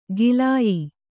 ♪ gilāyi